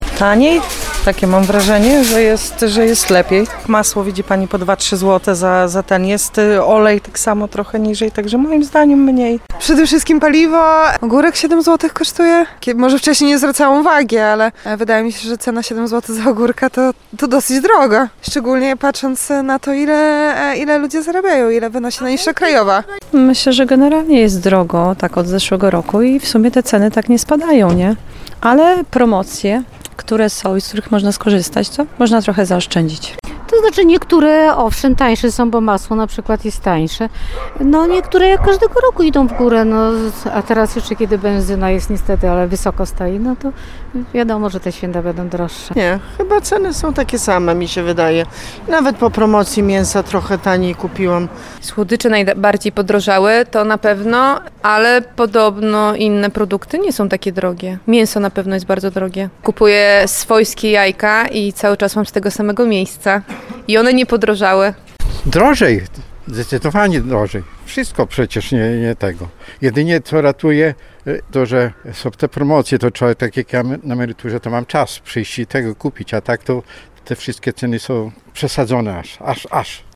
O to zapytaliśmy mieszkańców regionu.